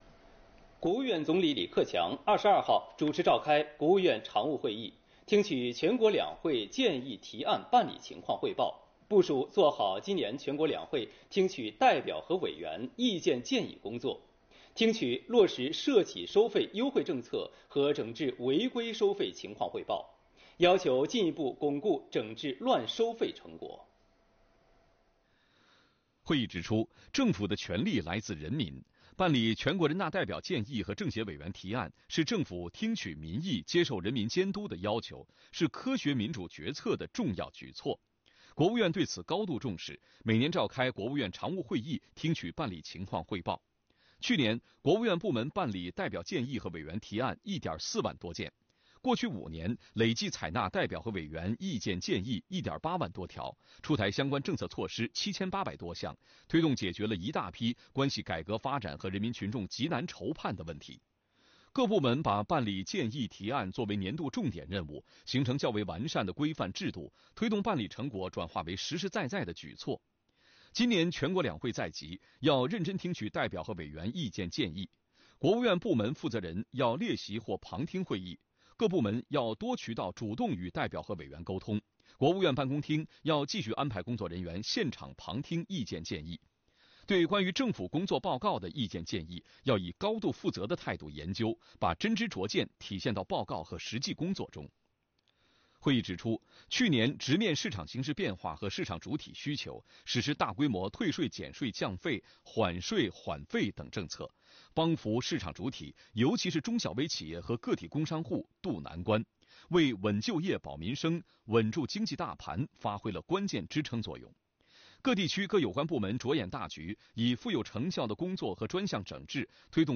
李克强主持召开国务院常务会议